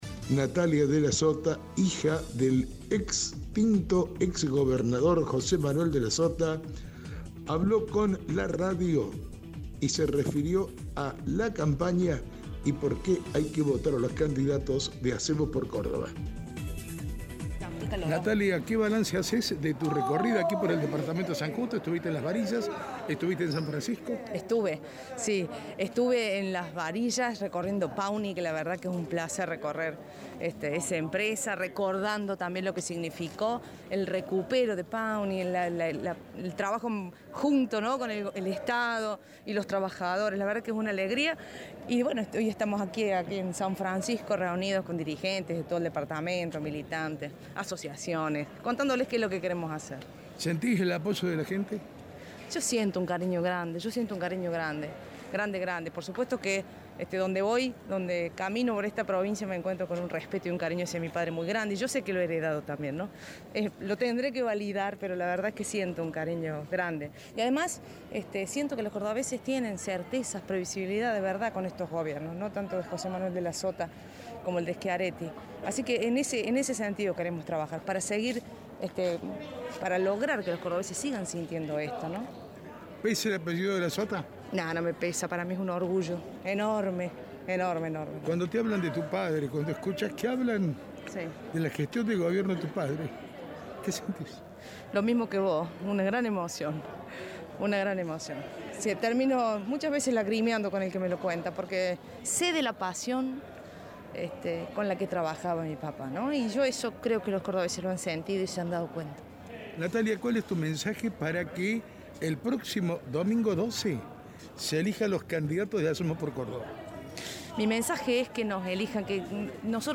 En su paso por San Francisco